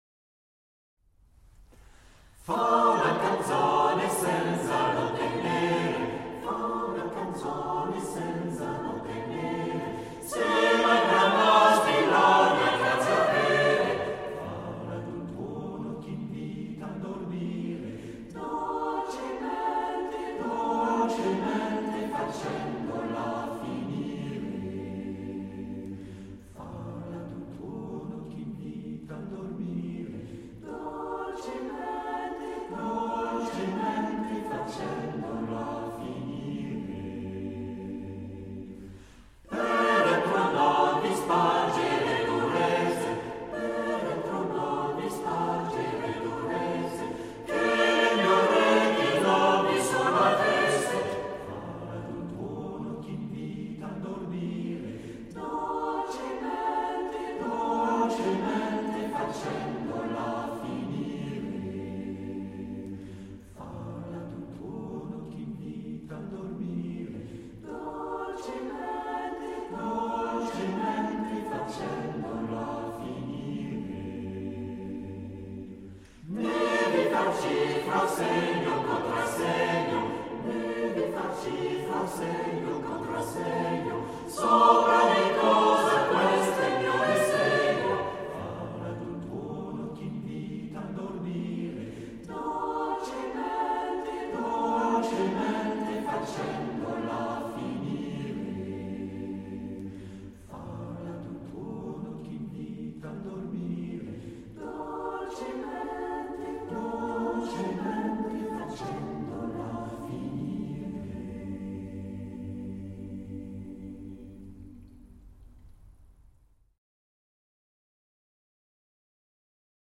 Pour un CD musical amateur de grande qualité